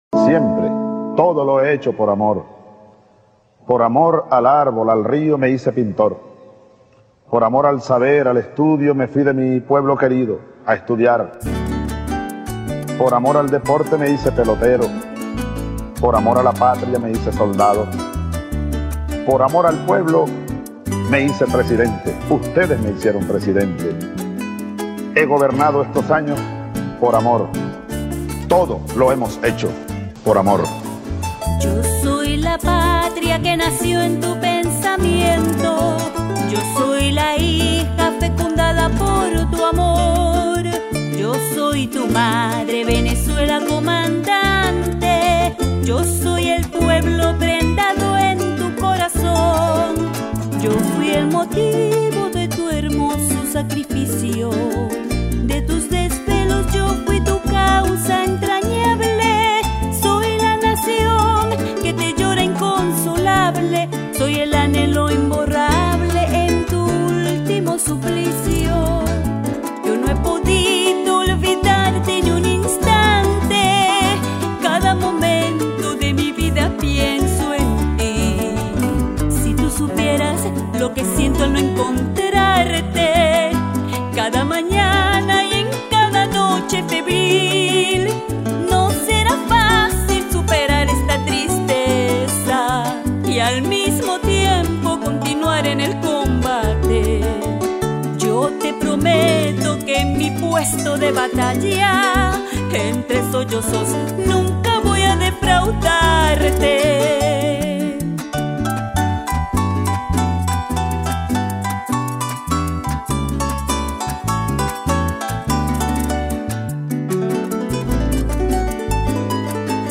arpa